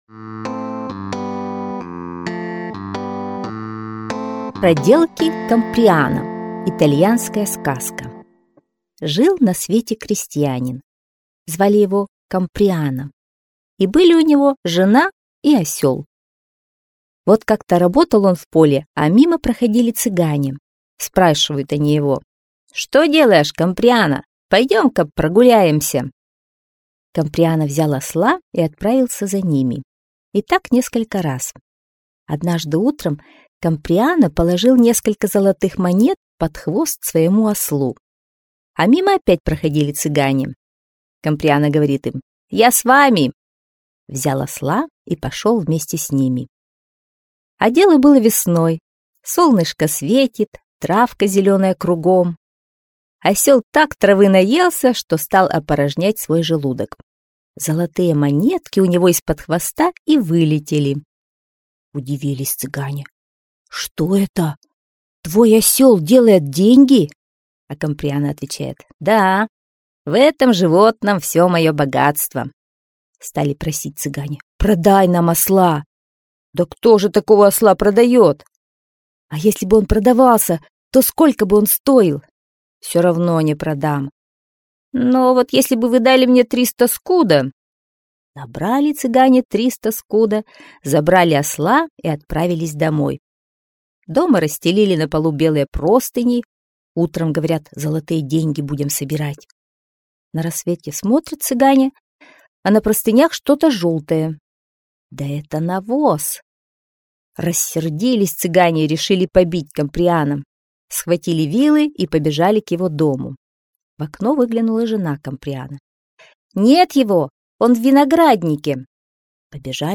Проделки Камприано - итальянская аудиосказка - слушать онлайн